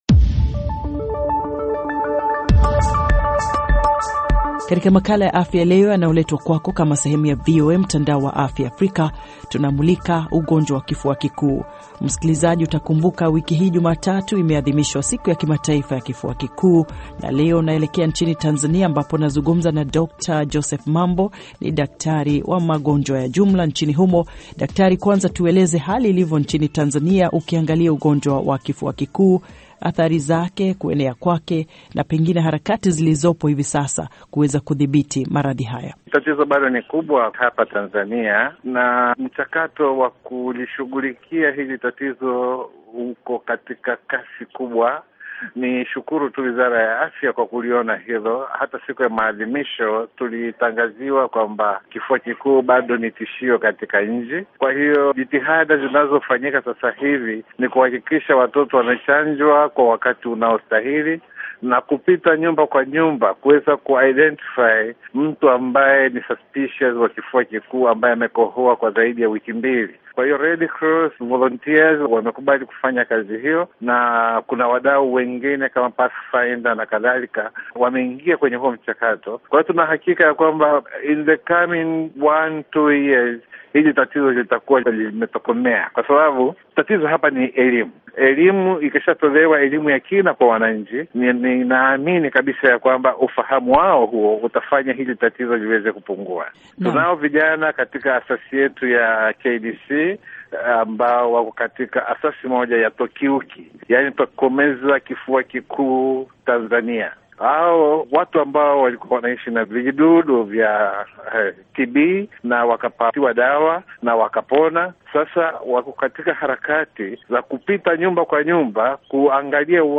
Afya: Kifua kikuu mahojiano - 5:31